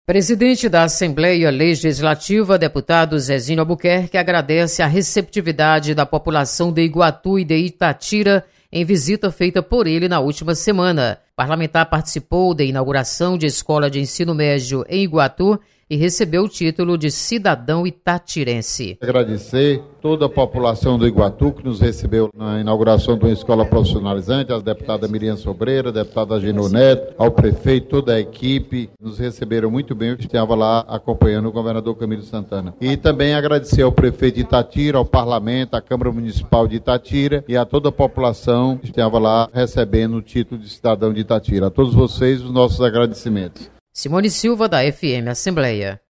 Presidente Zezinho Albuquerque agradece receptividade da população de Iguatu e Itatira.  Repórter